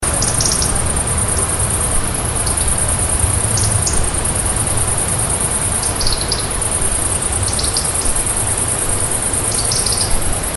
bysala-at-night_96.mp3